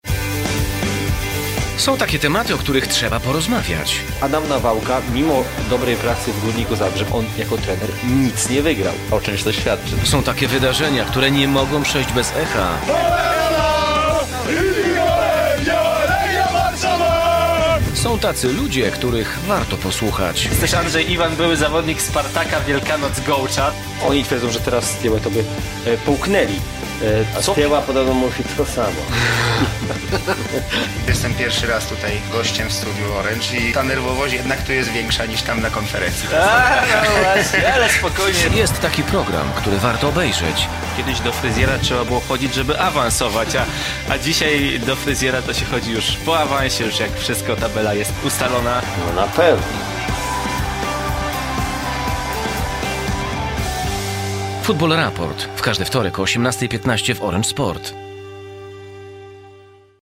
Male 30-50 lat
Nagranie lektorskie